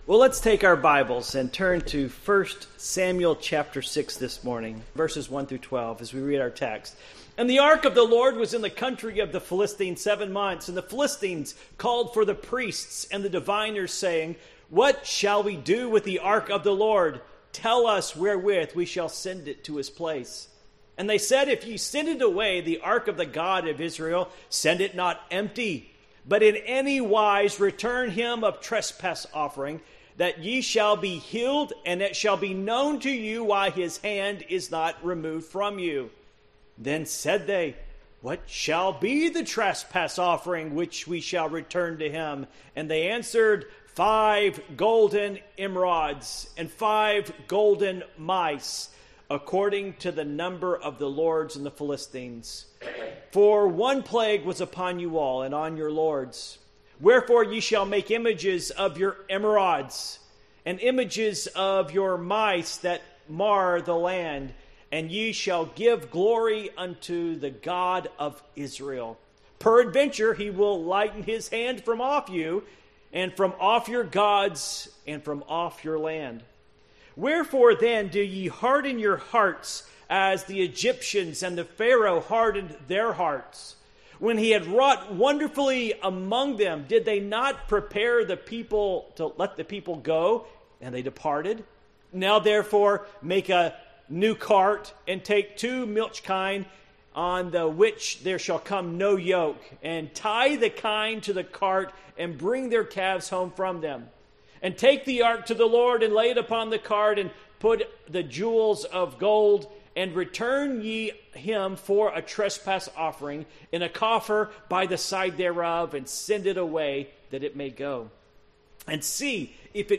Passage: 1 Samuel 6:1-12 Service Type: Morning Worship